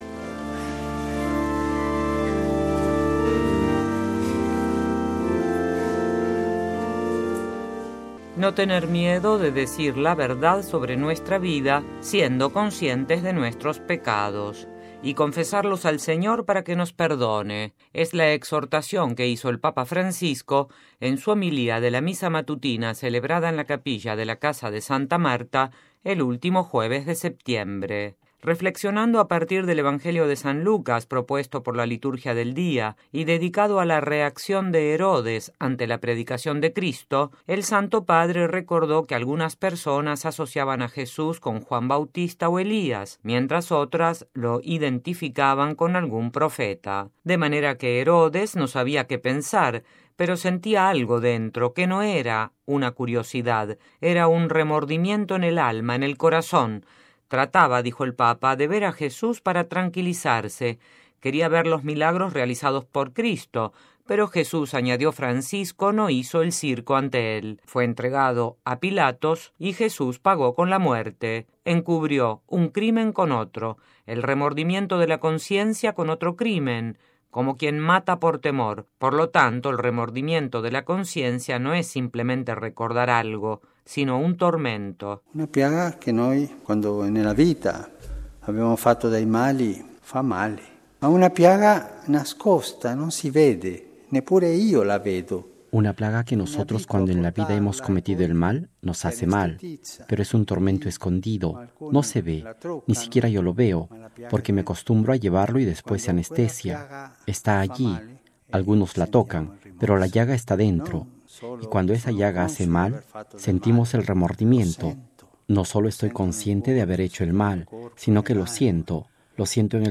Homilía de Papa: El remordimiento es síntoma de salvación
Es la exhortación que hizo el Papa Francisco en su homilía de la Misa matutina celebrada en la capilla de la Casa de Santa Marta, el último jueves de septiembre.